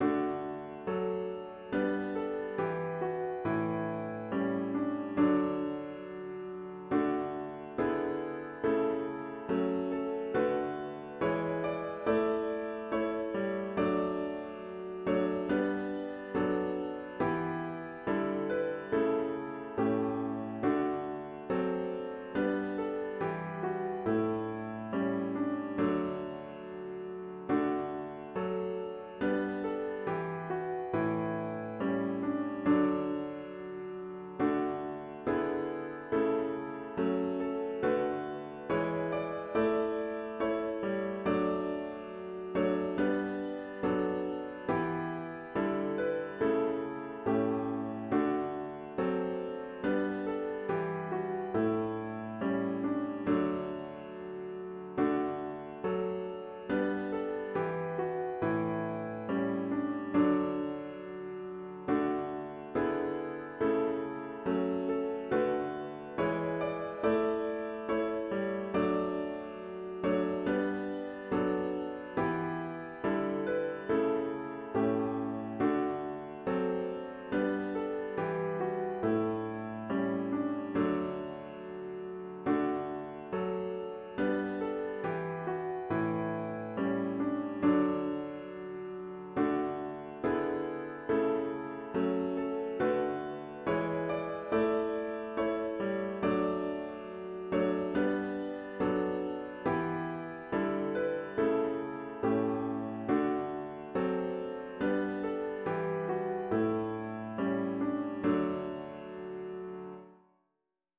CLOSING HYMN   “God Be with You Till We Meet Again”   GtG 541